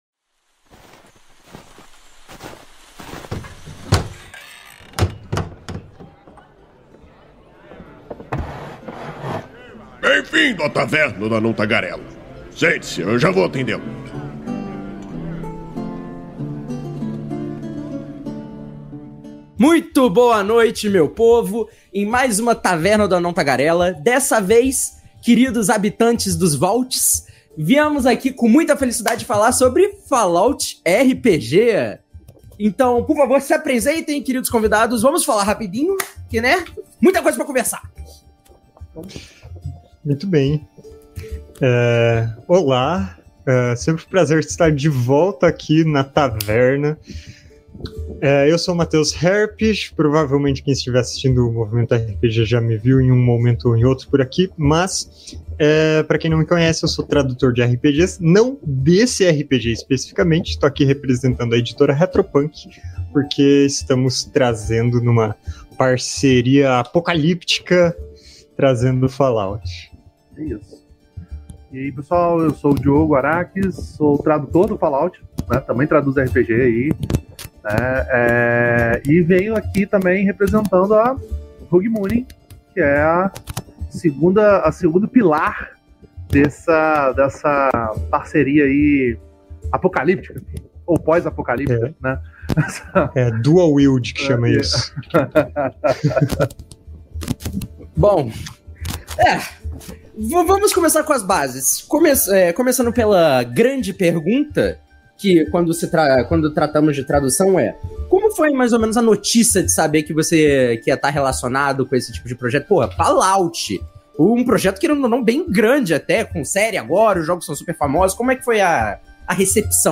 Venha saber mais sobre quais são as semelhanças desse sistema com as outras mídias de Fallout, entender como isso influencia as mecânicas do jogo e descobrir um pouquinho mais sobre as possibilidades desse vasto cenário. A Taverna do Anão Tagarela é uma iniciativa do site Movimento RPG, que vai ao ar ao vivo na Twitch toda a segunda-feira e posteriormente é convertida em Podcast.